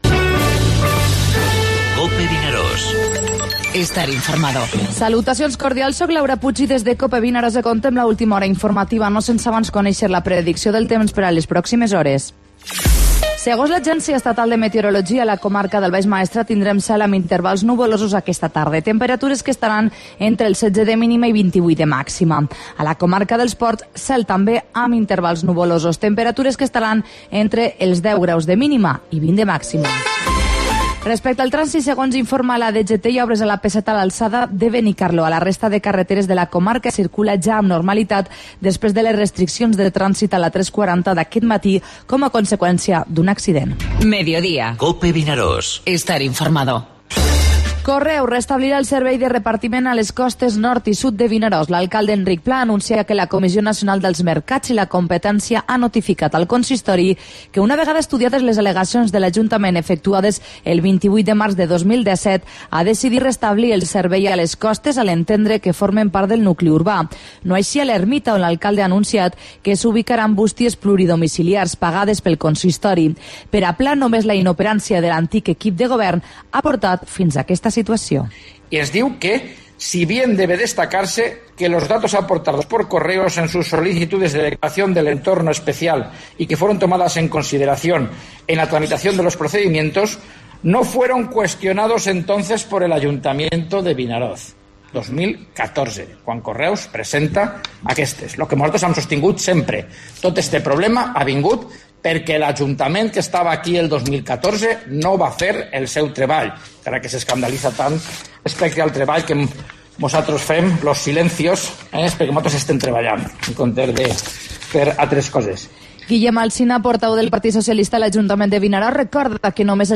Informativo Mediodía COPE al Maestrat (6/6/17)